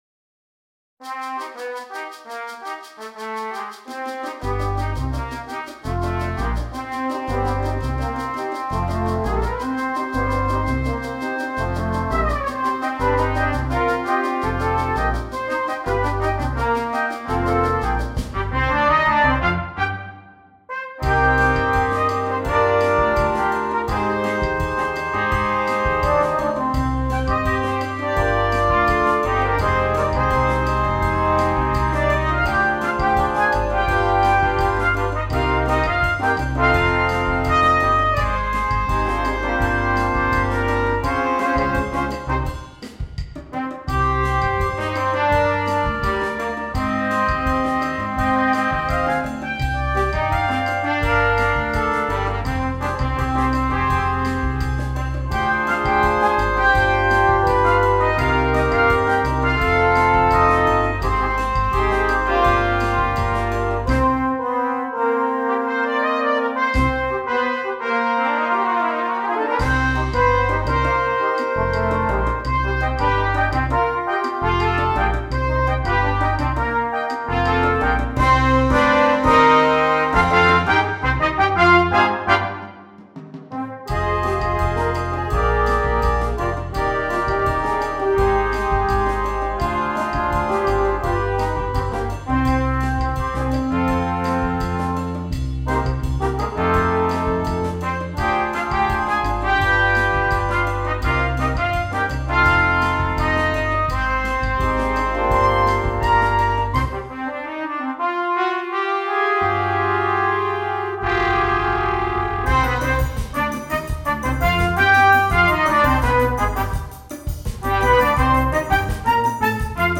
8 Trumpets